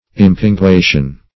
Search Result for " impinguation" : The Collaborative International Dictionary of English v.0.48: Impinguation \Im`pin*gua"tion\, n. The act of making fat, or the state of being fat or fattened.